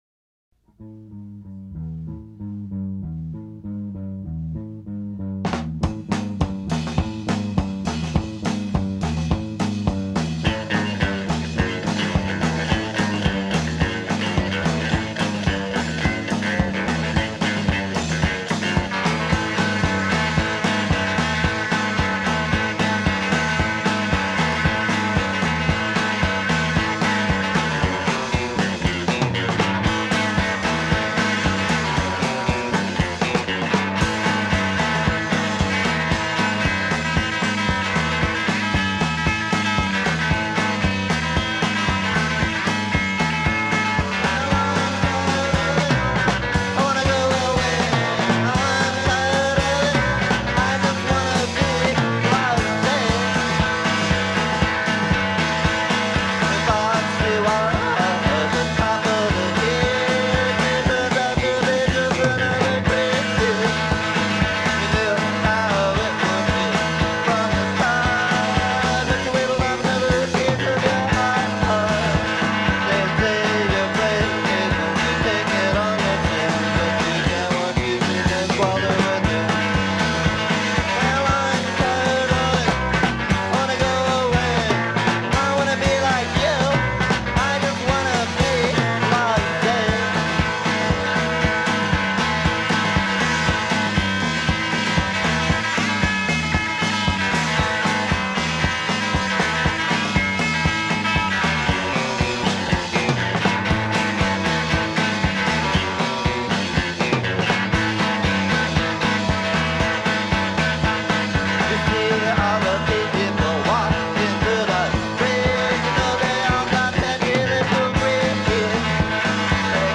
in the land of Tucson punk rock